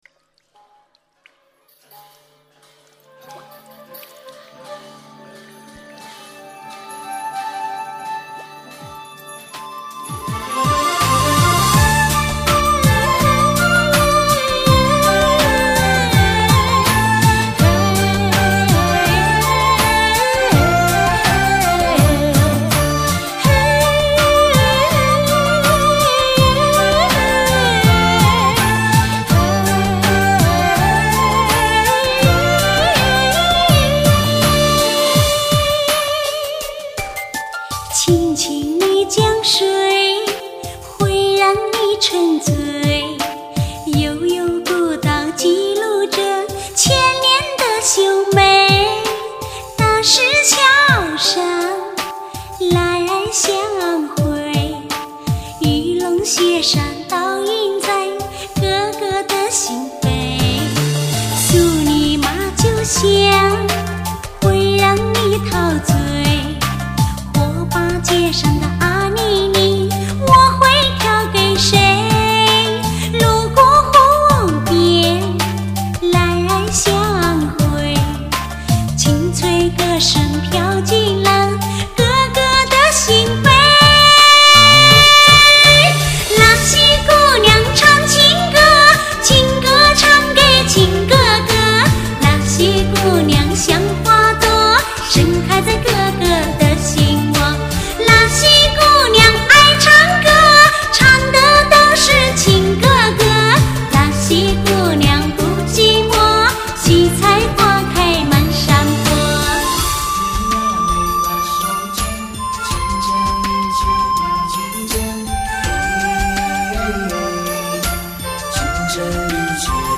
主打歌曲